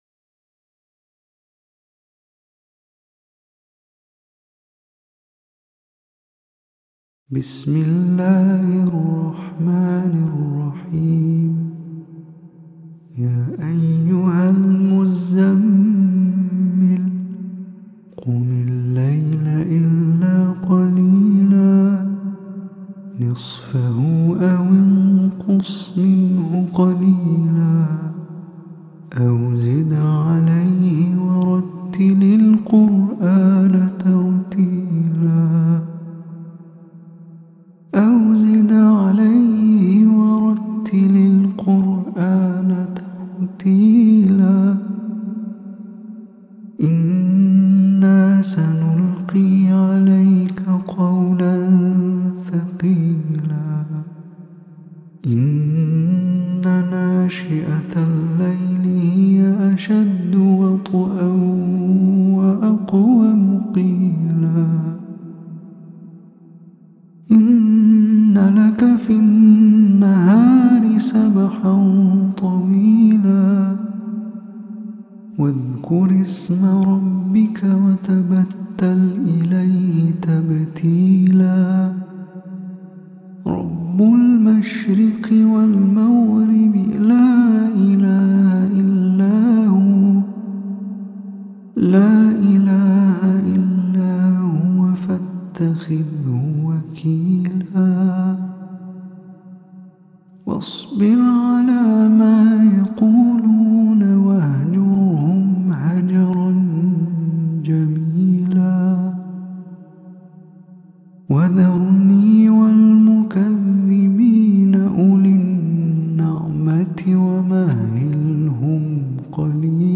سورہ مزمل کی ایک تلاوت بھی اس پوسٹ میں شامل ہے۔
Surah-Al-Muzzammil-_-MOST-PEACEFUL-QURAN-ASMR-سورة-المزمل-تلاوة-هادئة.mp3